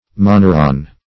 Moneron \Mo*ne"ron\, n.; pl. L. Monera; E. Monerons. [NL.]